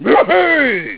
Mario Kart DS Sounds